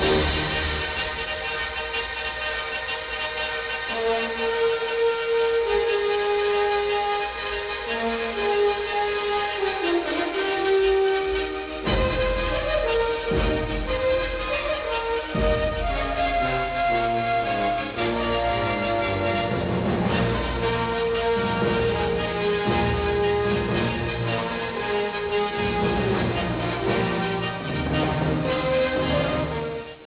Colonna sonora
di stile classico ed eseguite per orchestra
fiati e ottoni